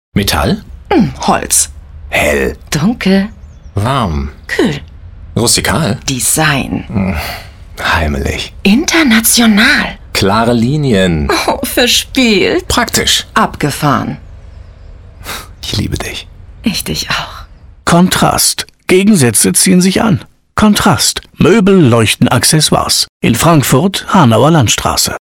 Sprechprobe: eLearning (Muttersprache):
german female voice over talent.